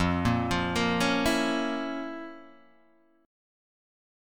F Major 7th Suspended 4th Sharp 5th